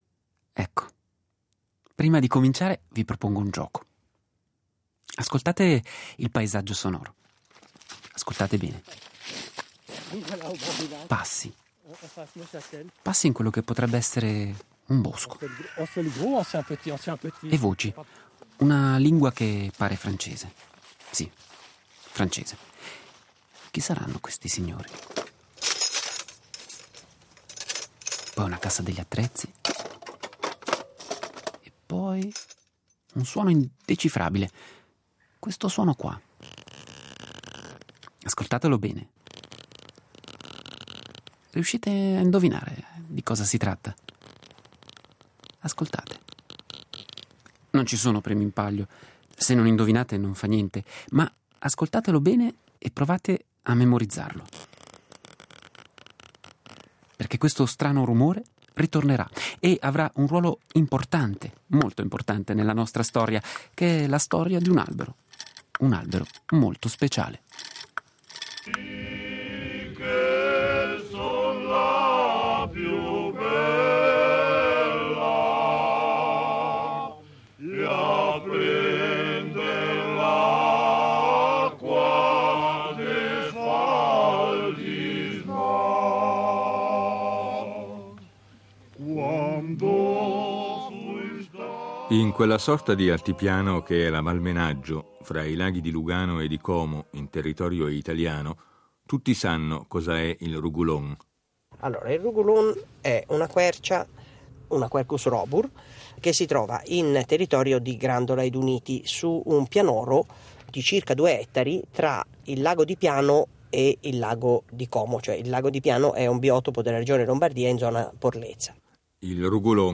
Ebbene, curiosamente, è proprio l'anagrafe di questa pianta ad animare questo racconto sonoro, specie di giallo tinto di verde, testimonianza del rapporto speciale di una comunità umana nei confronti di un albero.